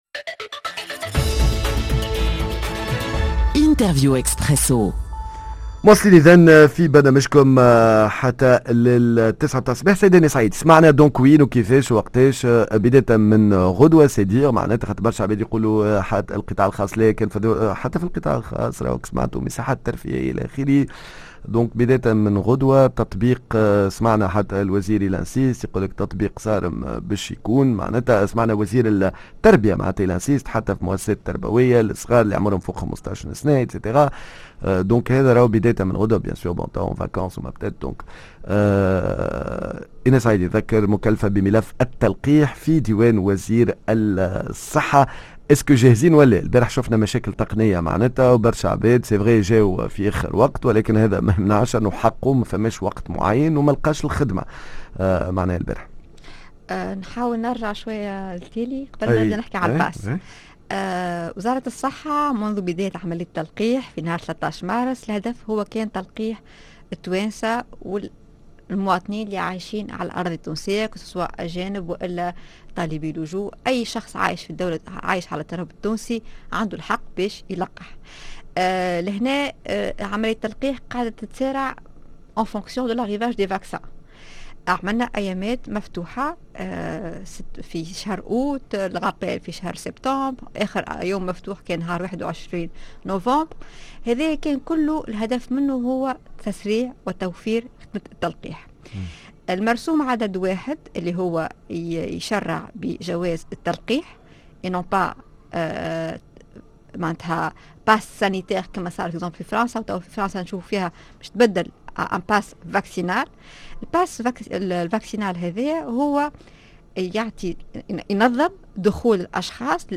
جواز التلقيح ضد فيروس كورونا و إجراءات تطبيق القانون المتعلق به مع ضيفتنا